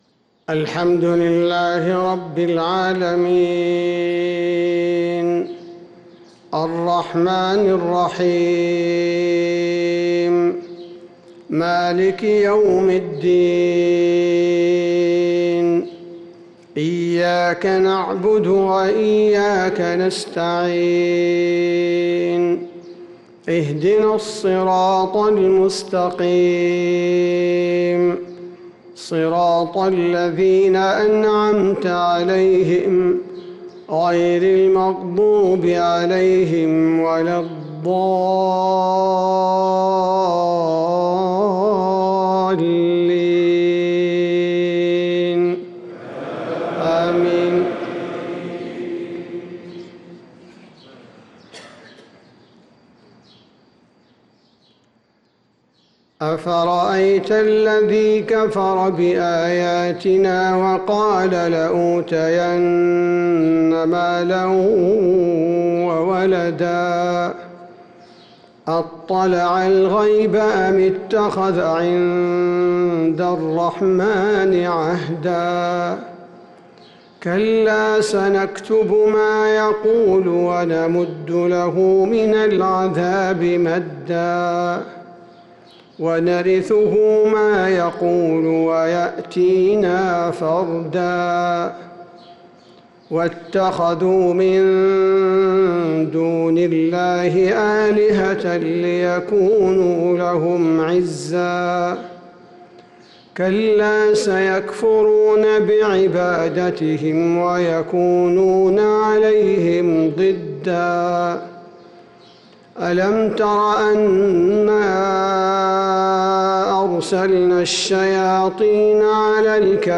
فجر الأحد 9-9-1446هـ خواتيم سورة مريم 77-98 | Fajr prayer from Surat Maryam 9-3-2025 > 1446 🕌 > الفروض - تلاوات الحرمين